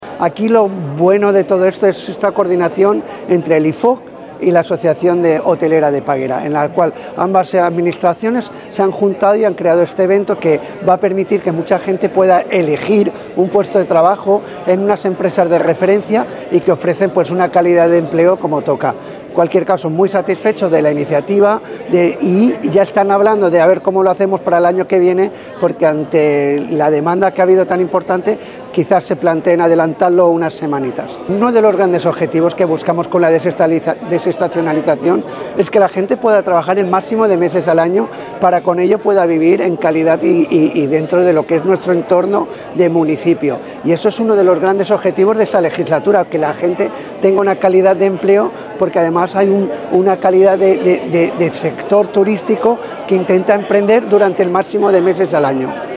declaraciones-alcalde-juan-antonio-amengual.mp3